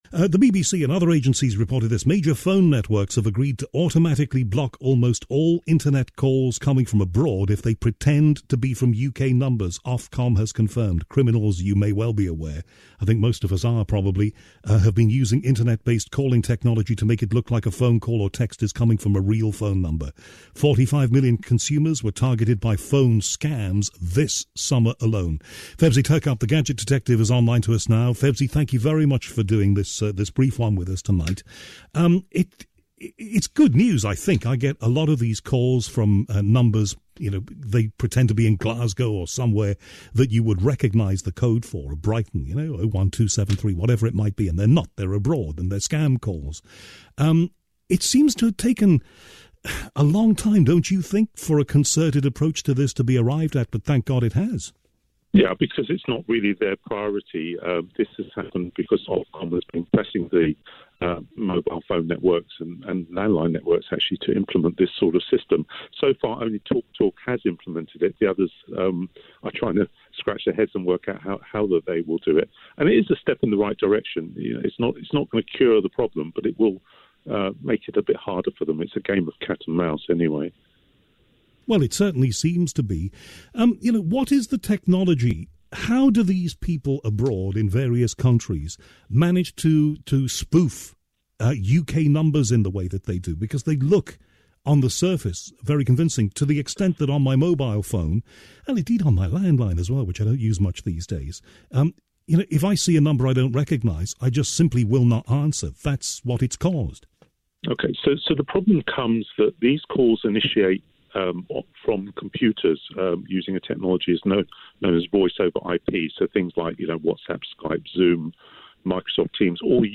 31st October 2021 - Discussing Fake Numbers on TalkRADIO